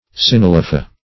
Search Result for " synalepha" : The Collaborative International Dictionary of English v.0.48: Synalepha \Syn`a*le"pha\, n. [NL., fr. L. synaloepha, Gr.